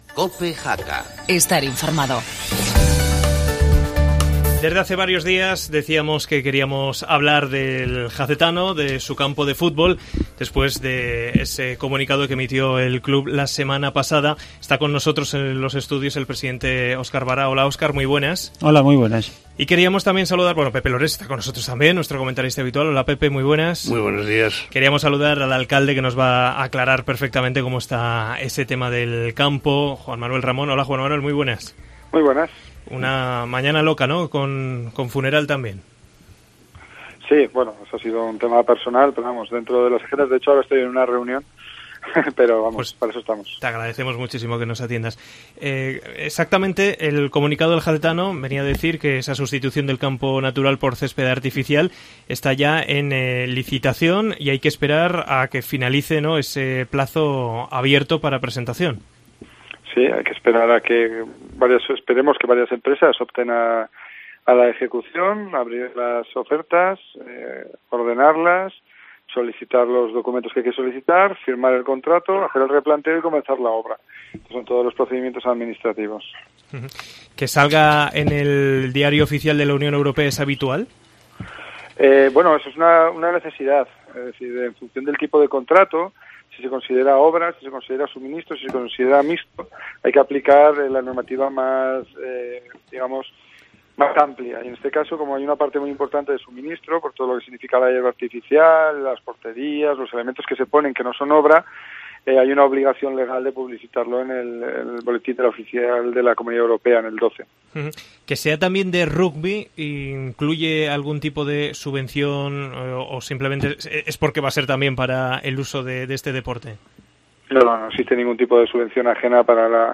Debate sobre el campo del Oroel